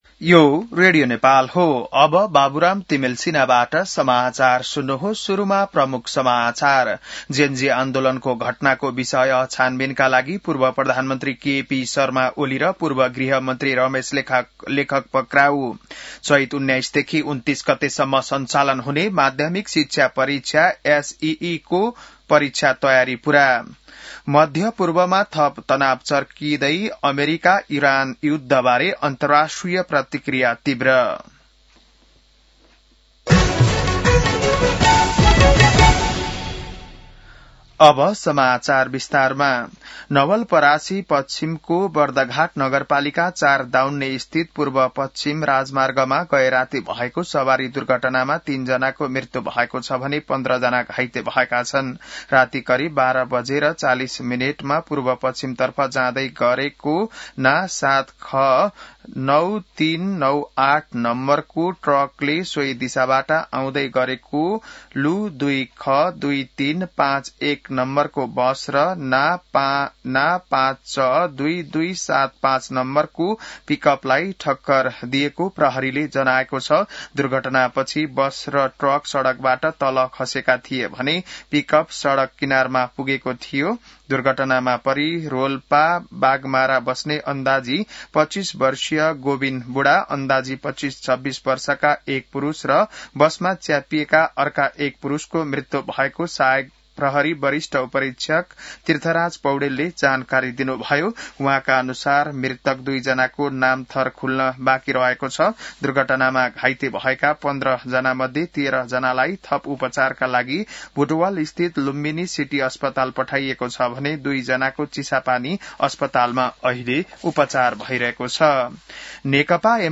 बिहान ९ बजेको नेपाली समाचार : १४ चैत , २०८२